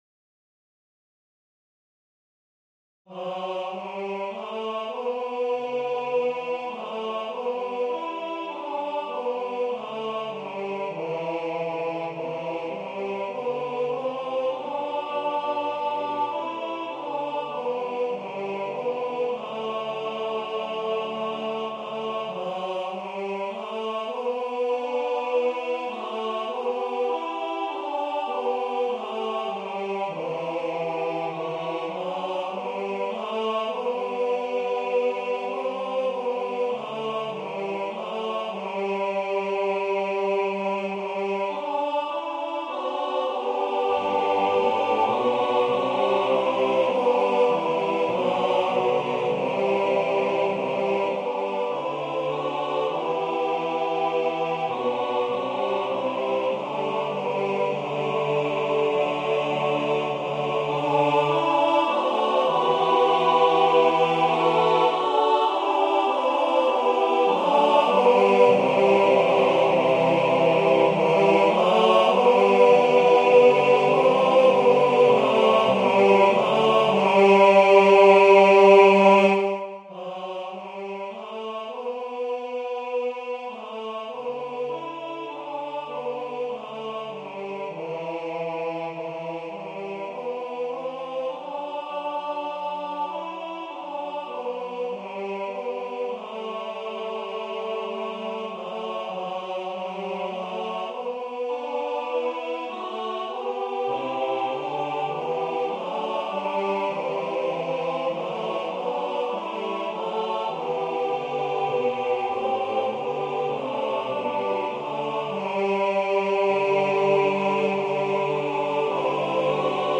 Solo